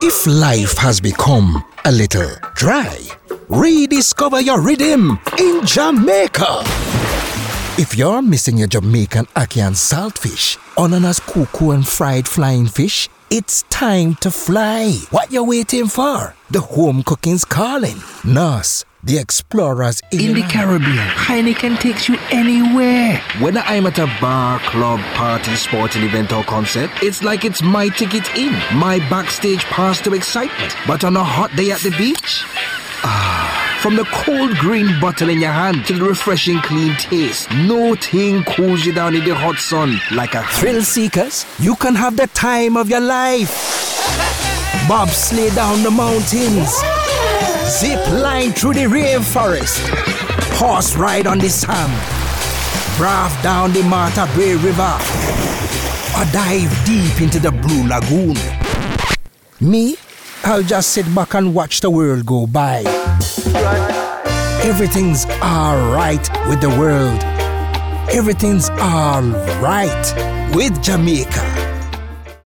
Englisch (Karibik)
Vertrauenswürdig
Warm
Konversation